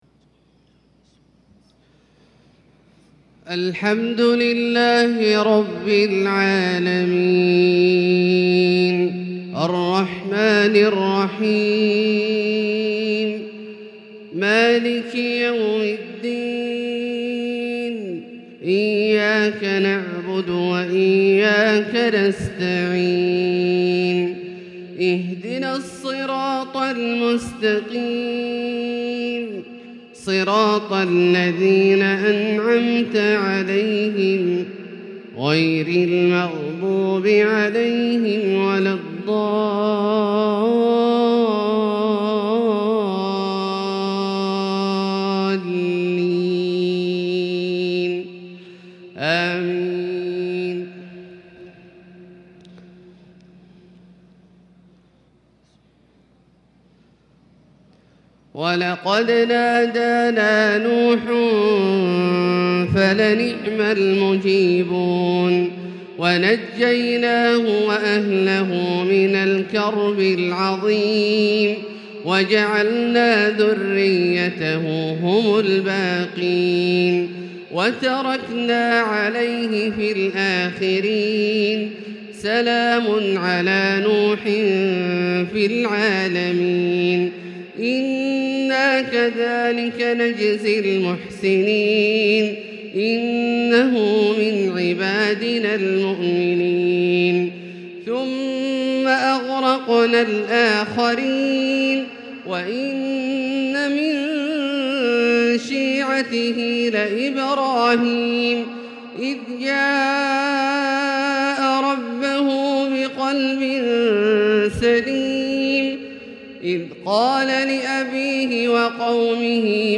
فجر عيد الأضحى 1442هـ من سورة الصافات {75-122} > ١٤٤٢ هـ > الفروض - تلاوات عبدالله الجهني